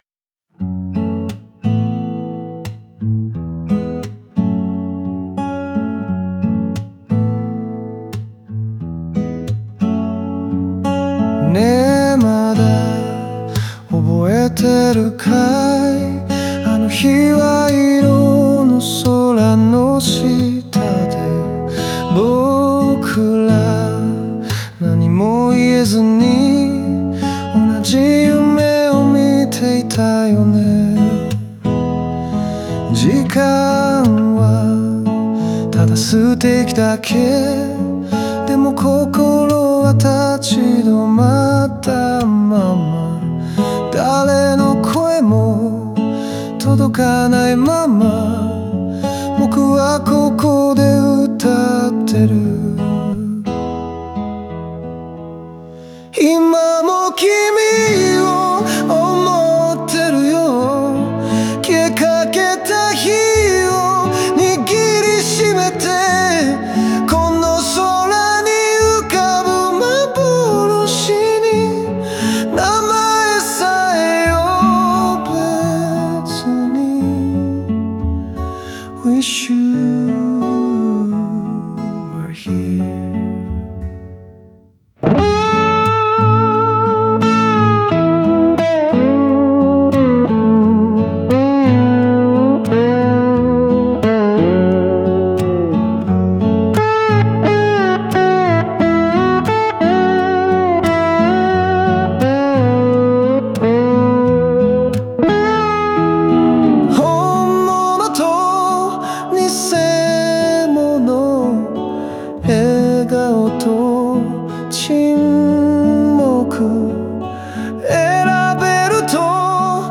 ギターの余白や風の音が、その感情を包み込み、聴き手に静かな余韻を与えます。